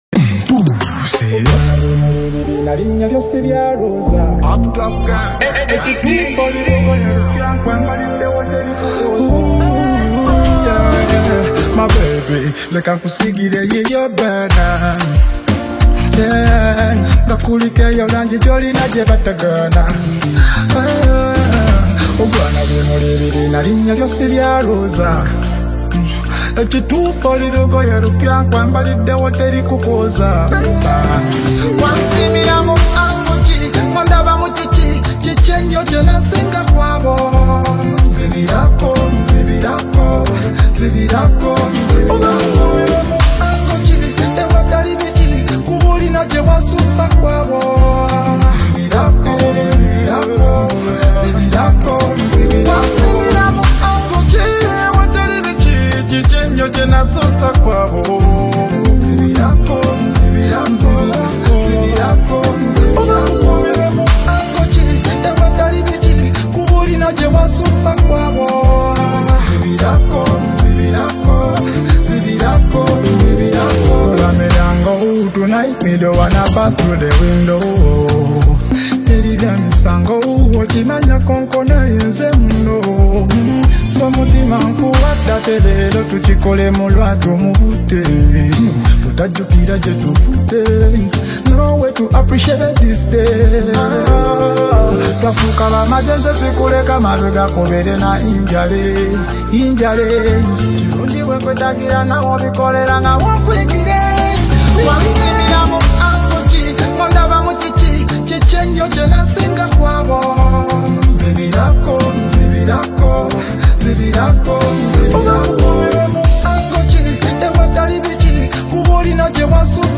fresh Afro-Pop single
dynamic vocals